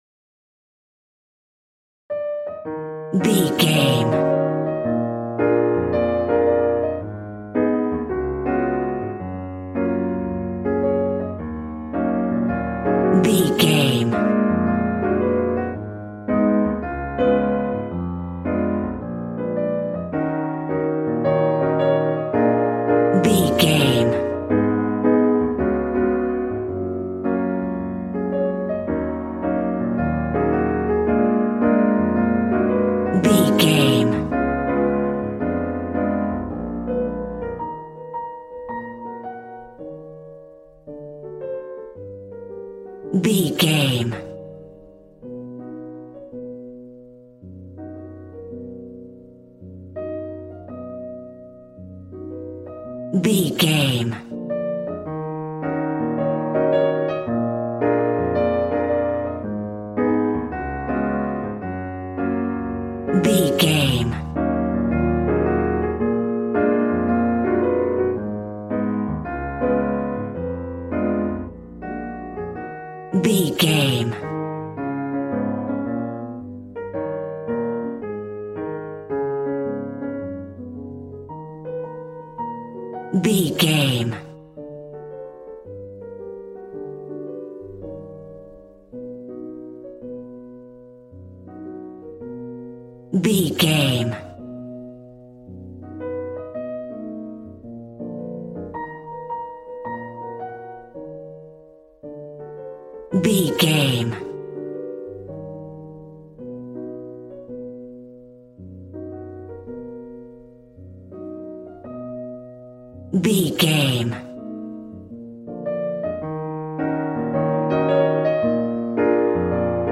Smooth jazz piano mixed with jazz bass and cool jazz drums.,
Ionian/Major
smooth
piano
drums